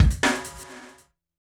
kick-snare.wav